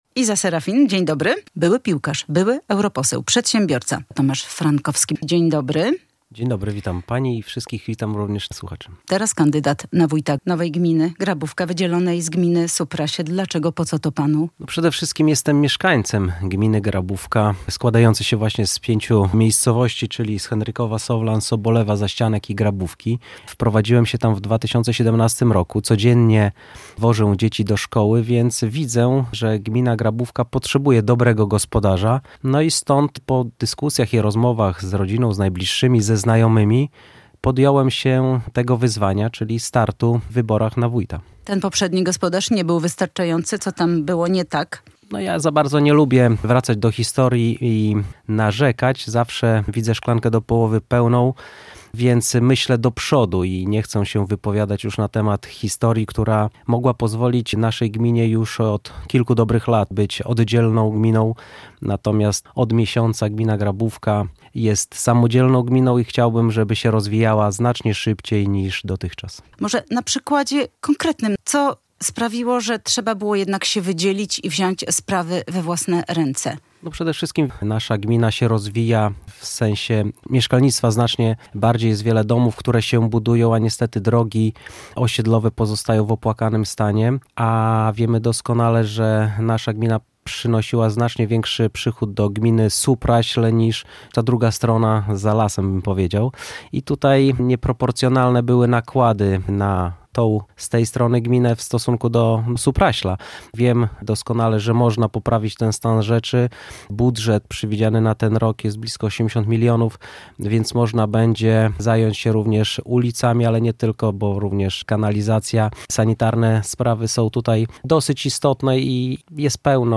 Były piłkarz, były europoseł i przedsiębiorca - Tomasz Frankowski - ogłosił swój start w wyborach na wójta nowo powstałej gminy Grabówka. W Rozmowie Dnia w Polskim Radiu Białystok wyjaśnia, dlaczego zdecydował się na ten krok i jakie ma plany dla swojej gminy.